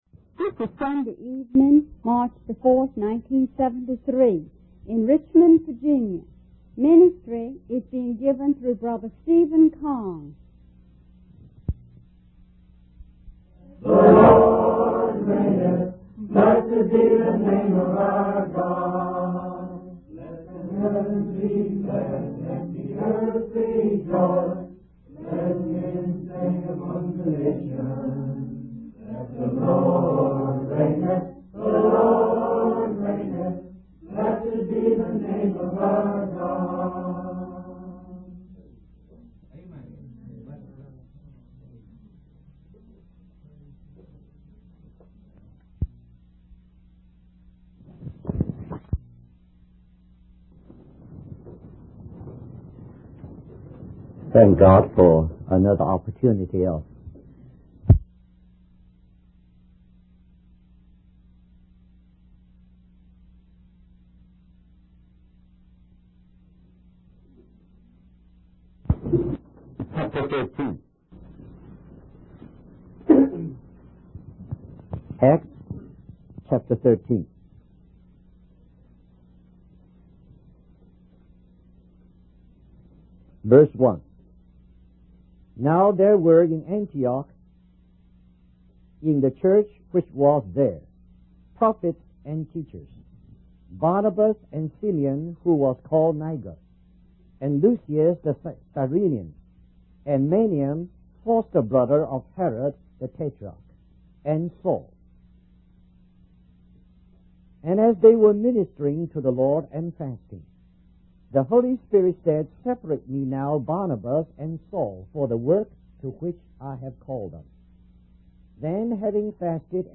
In this sermon, the speaker discusses the importance of the church in taking care of children and the weak.